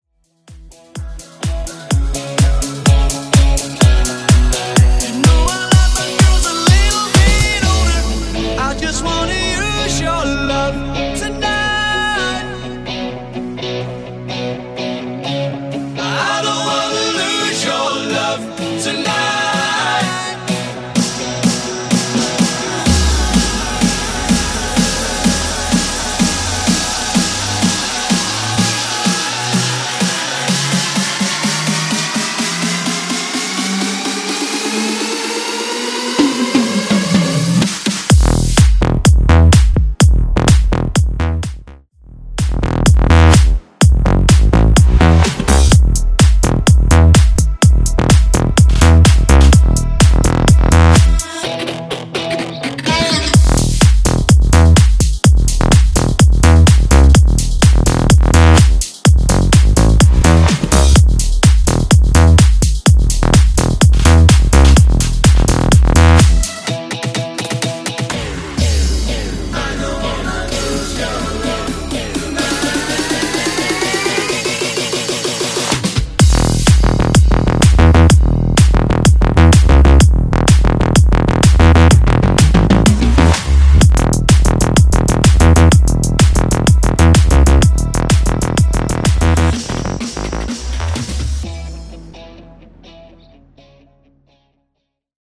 Genre: 90's
Clean BPM: 126 Time